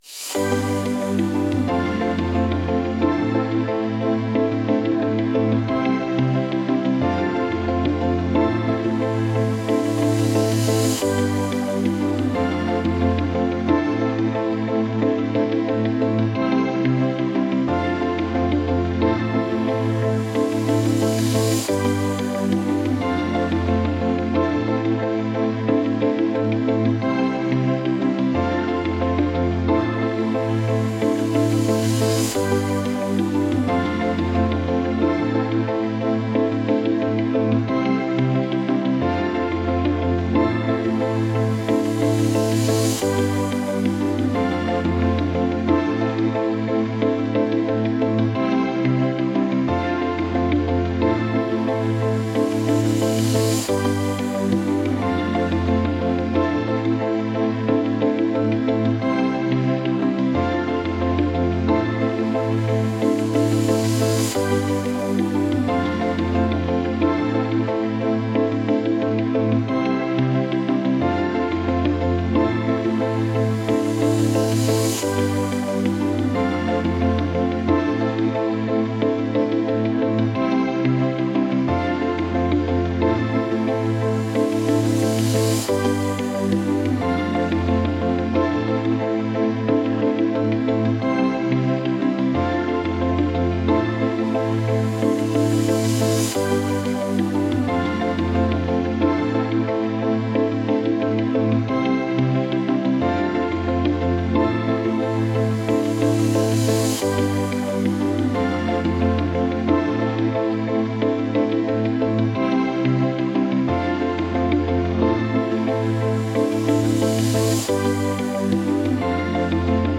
groovy | pop